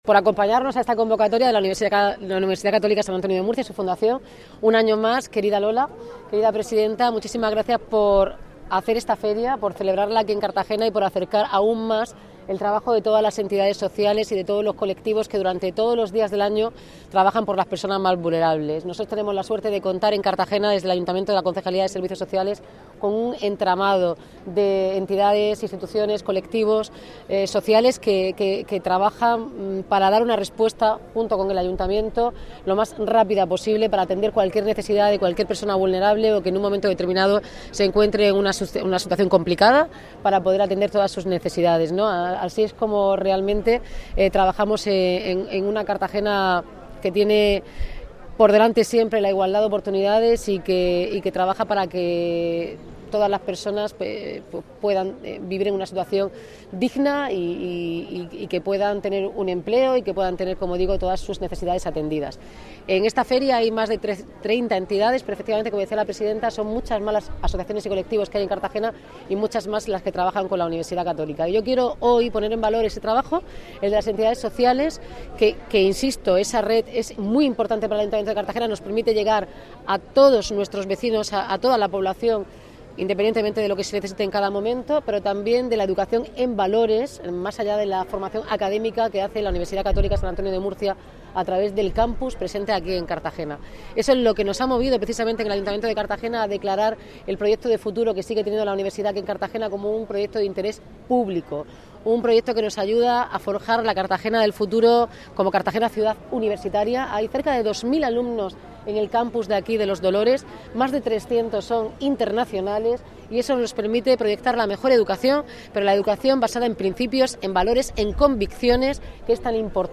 Inauguración de la IX Muestra de Voluntariado de la UCAM de Cartagena